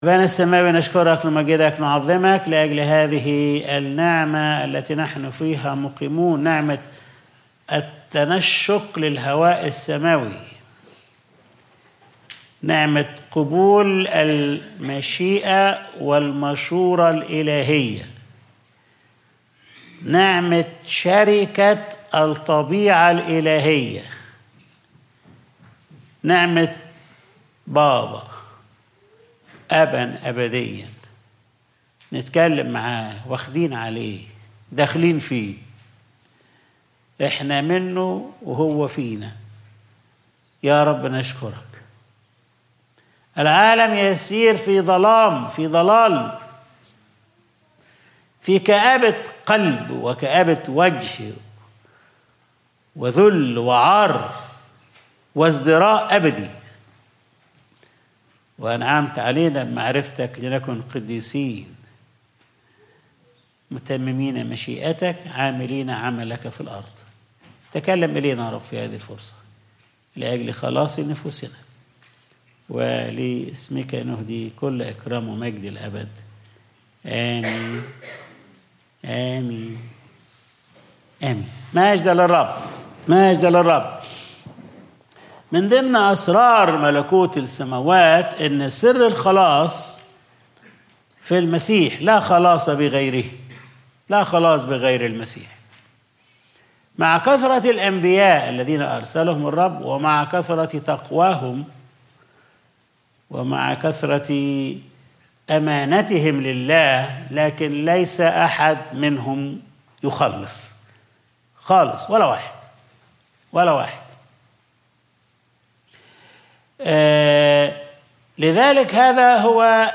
Sunday Service | من هو مستحق